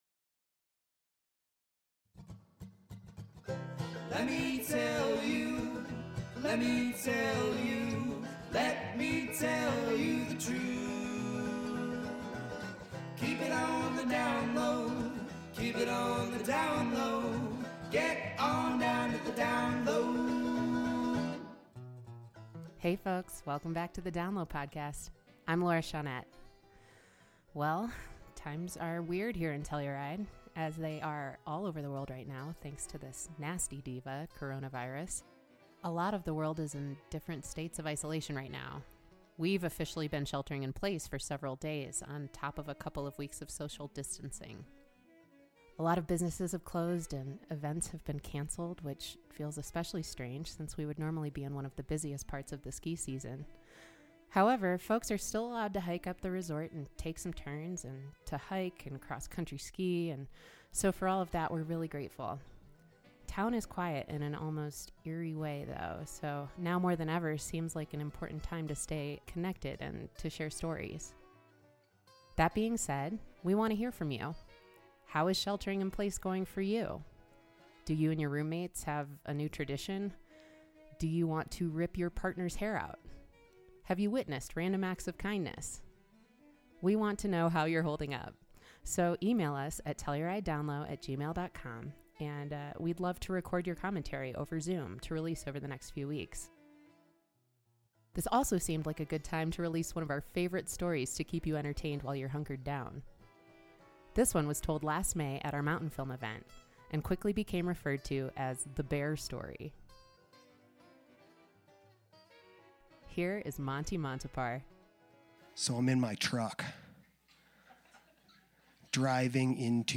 told at our Mountainfilm event in 2019